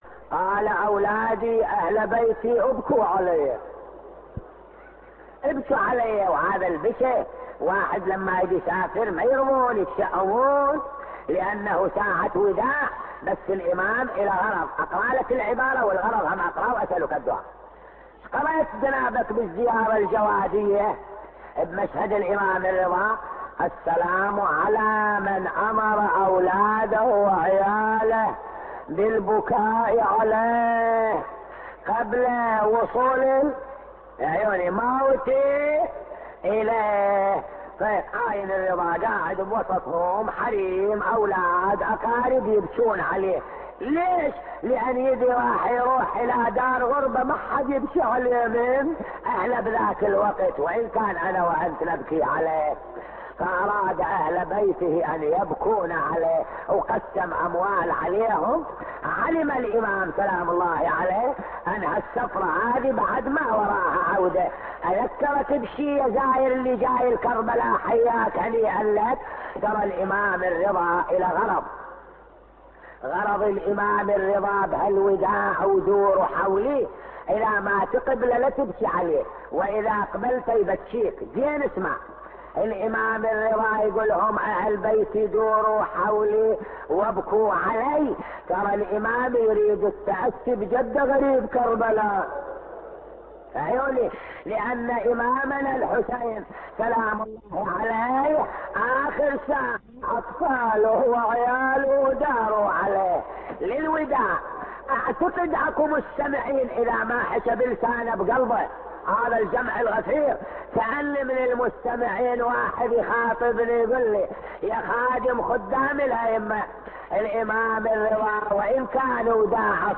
نواعي حسينية 1